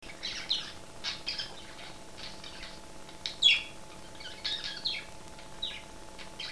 Budgie Birds - Vocals
[n.b. The Budgies calls had to be increased in volume,
thus increasing background computor hum.]
The Four Budgies Vocalising - 11th November 2007 [1].